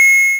Pickup_Coin13.mp3